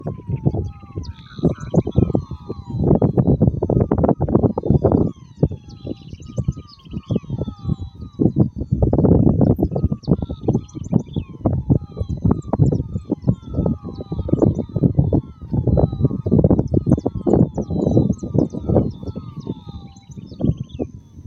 Menwig Frog (Physalaemus albonotatus)
Life Stage: Adult
Province / Department: Entre Ríos
Location or protected area: Ceibas
Condition: Wild
Certainty: Recorded vocal